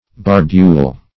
Barbule \Bar"bule\, n. [L. barbula, fr. barba beard.]